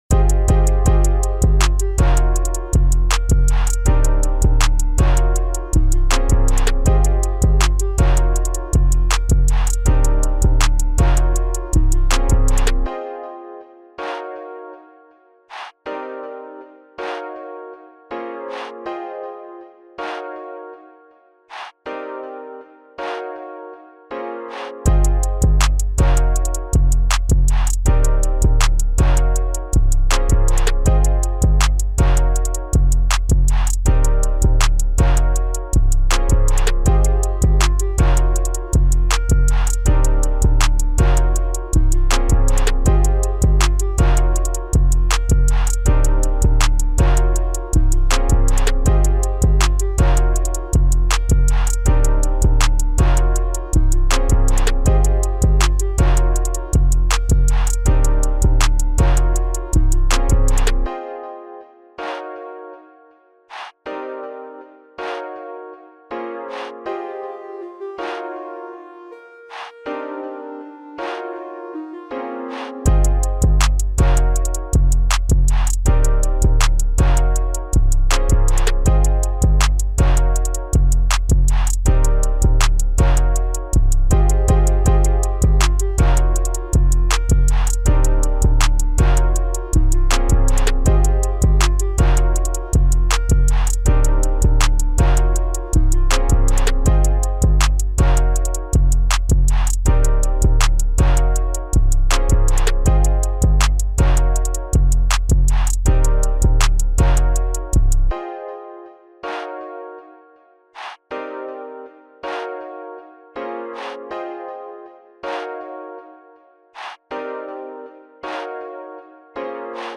official instrumental
Rap Instrumental